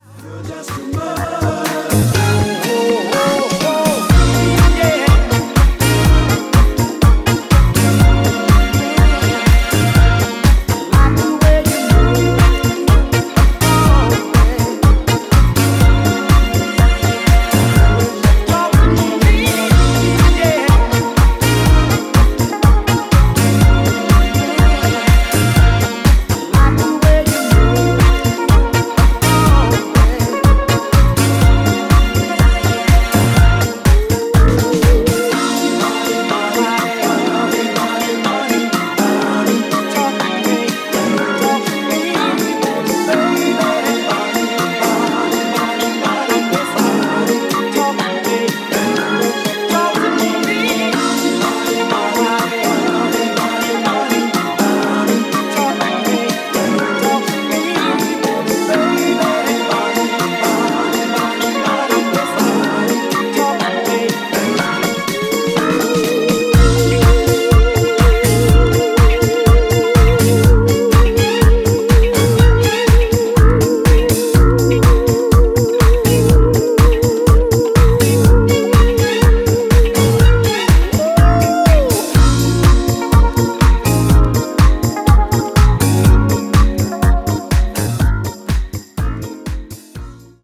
ファンキーでソウルフル！
ジャンル(スタイル) DISCO HOUSE / RE-EDIT